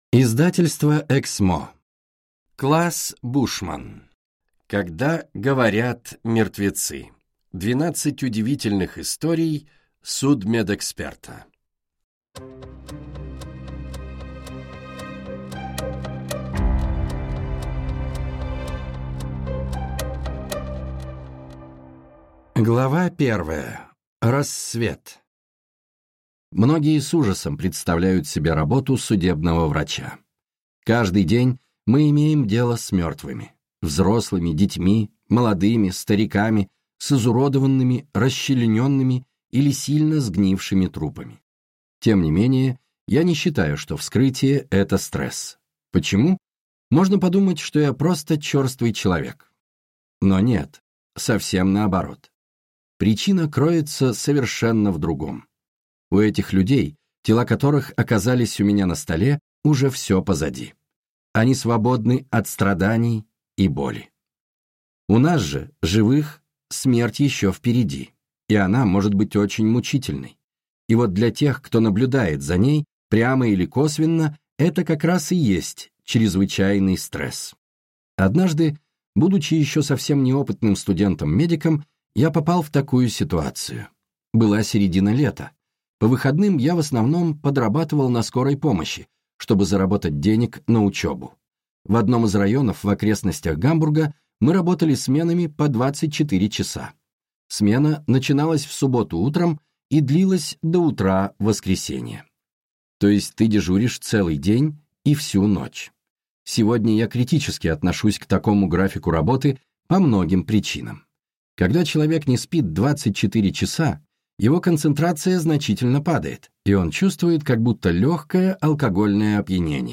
Аудиокнига Когда говорят мертвецы. 12 удивительных историй судмедэксперта | Библиотека аудиокниг